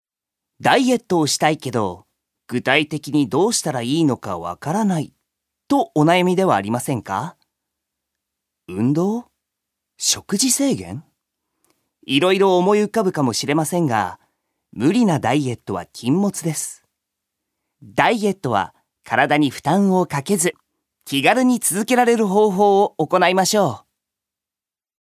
所属：男性タレント
ナレーション１